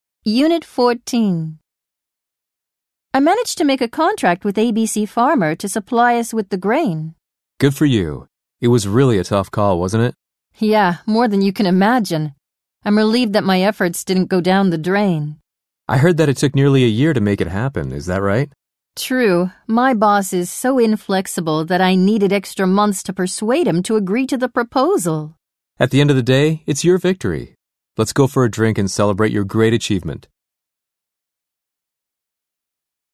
・ダイアローグの音声サンプル
・ナレーター：アメリカ英語のネイティブ２名（男女）
・スピード：ナチュラル